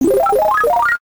fanfare